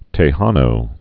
(tā-hänō, tĕ-)